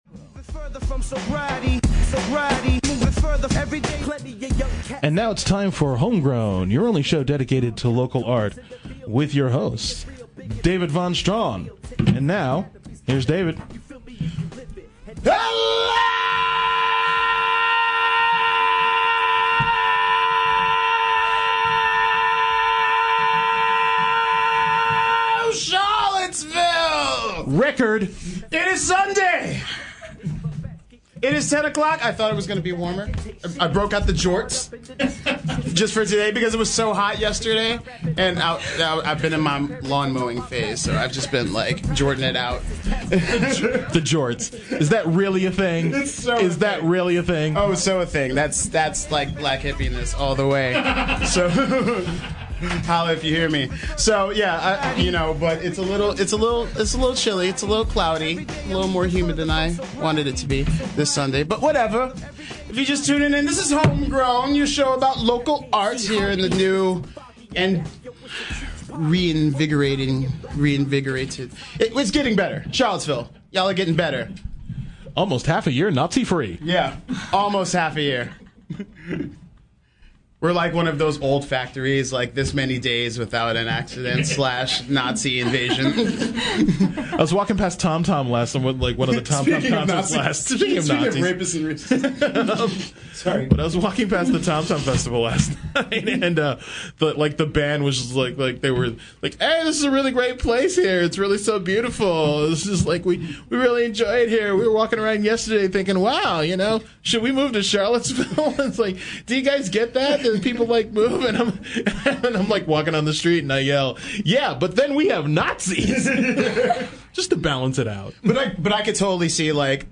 We also hear a piece of original music she’s written for the show.